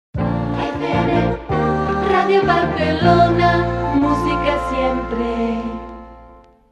Jingle